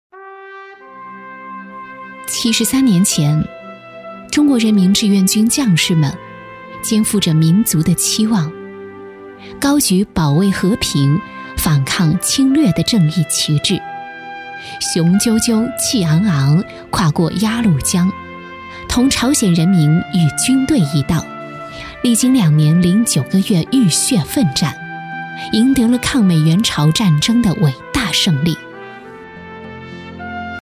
纪录片-女21-暖心-中国志愿军.mp3